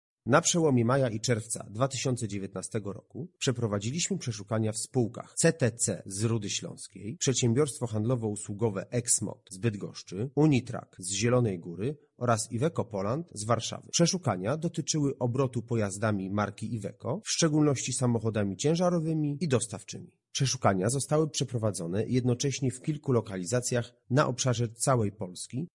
O tym w jakich przedsiębiorstwach przeprowadzono kontrole mówi Michał Holeksa, wiceprezes UOKiK: